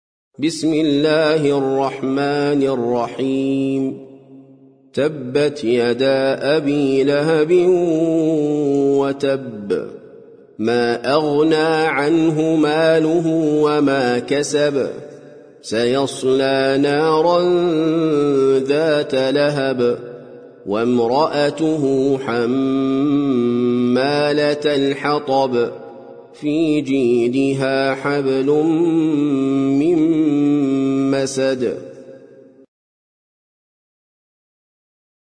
سورة المسد - المصحف المرتل (برواية حفص عن عاصم)
جودة عالية